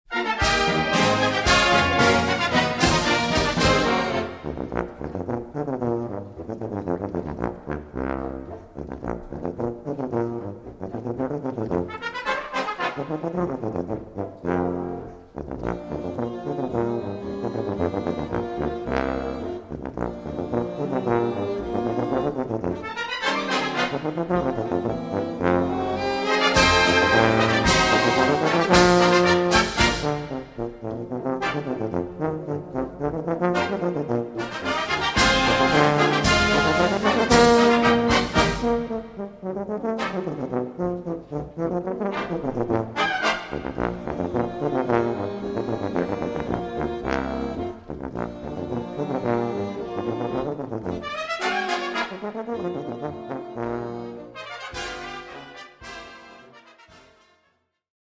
Tuba Solo & Blasorchester